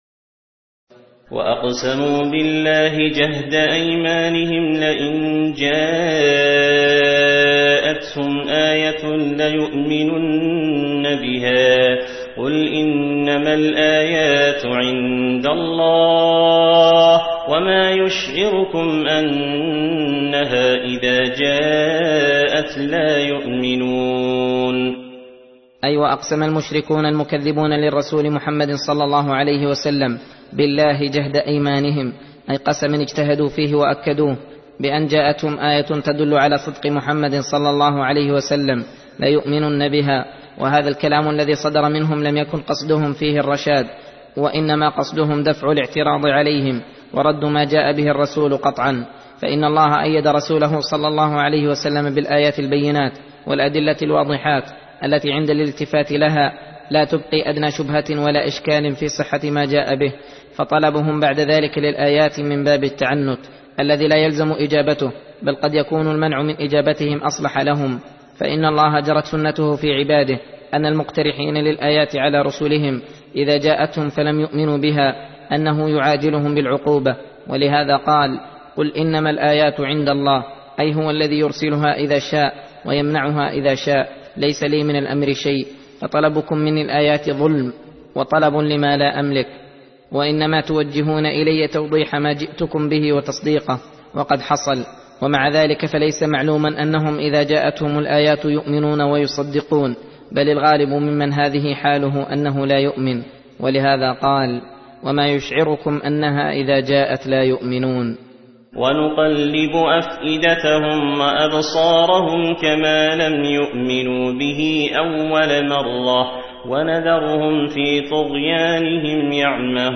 درس (31) : تفسير سورة الأنعام : (109-121)